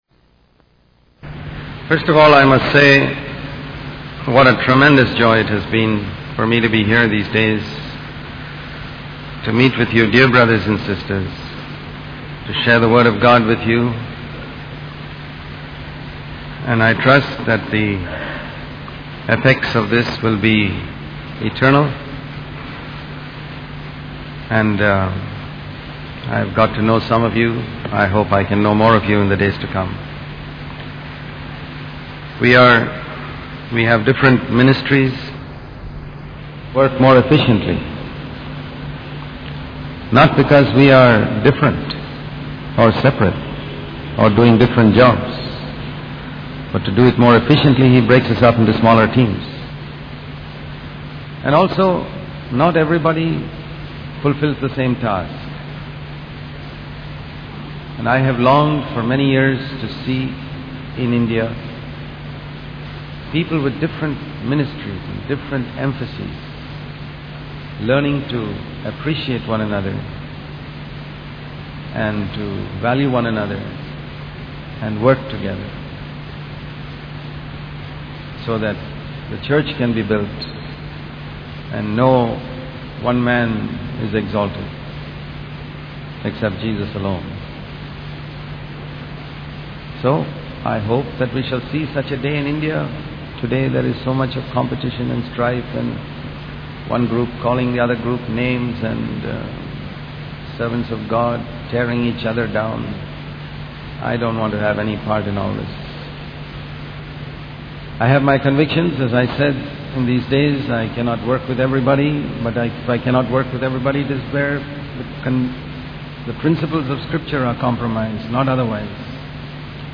In this sermon, the speaker emphasizes the importance of keeping a sensitive conscience and living a life that honors Christ. He encourages believers to always be ready to defend their faith with gentleness and reverence.